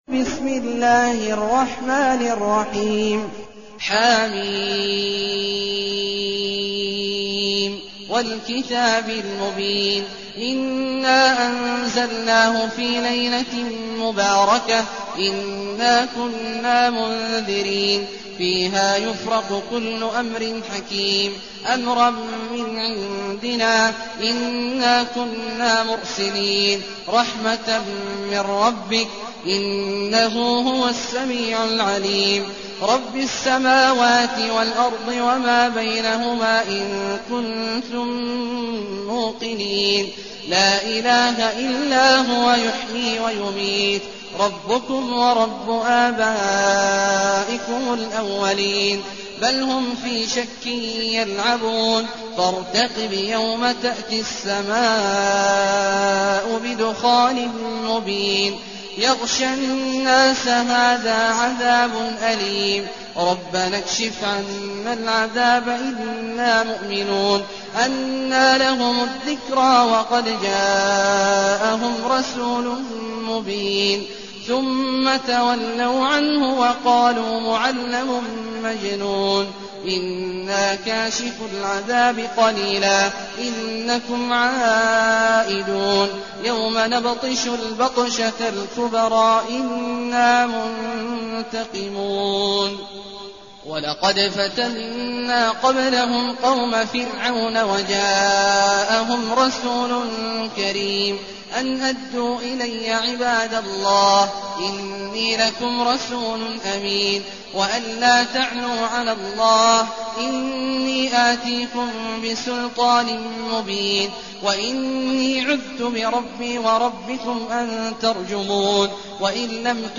المكان: المسجد النبوي الشيخ: فضيلة الشيخ عبدالله الجهني فضيلة الشيخ عبدالله الجهني الدخان